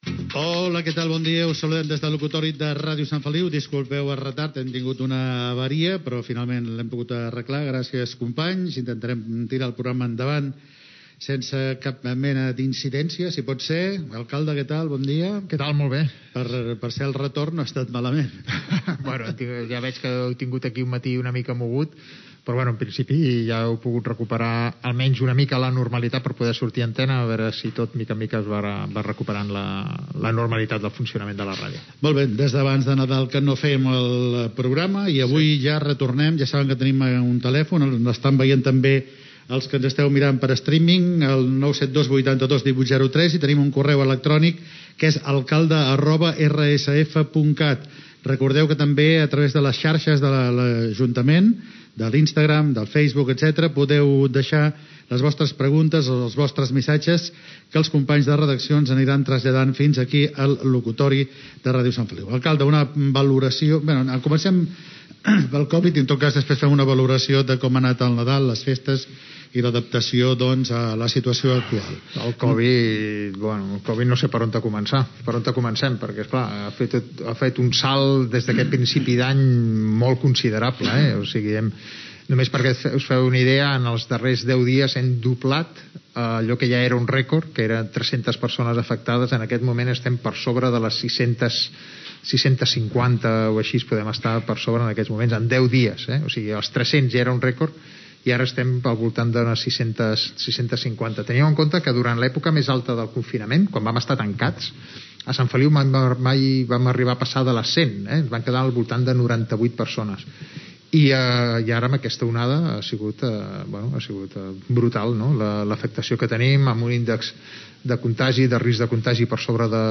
Formes de contactar amb el programa, l'alcalde Carles Motas dona dades sobre la situació de la pandèmia de la Covid 19.